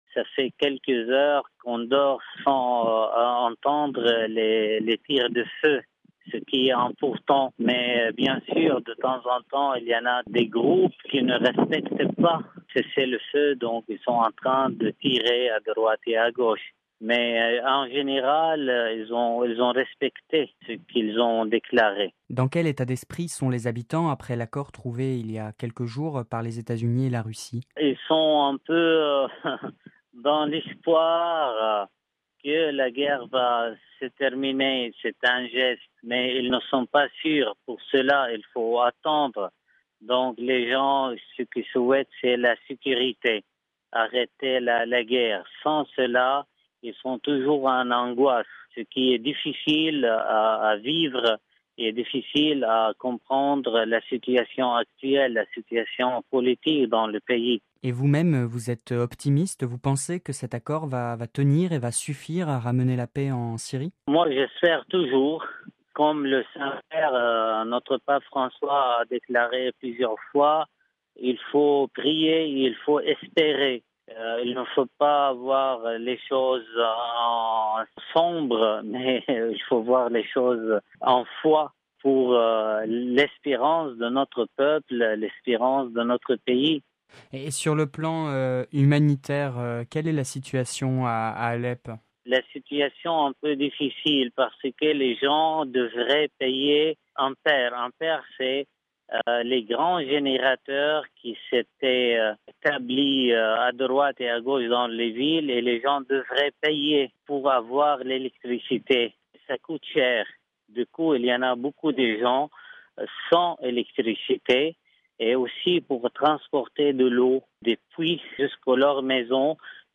(RV) Entretien – Ce jeudi 15 septembre 2016, l’Organisation des Nations Unies a annoncé que son convoi d’aide humanitaire à destination d’Alep, en Syrie, avait franchi la frontière turque.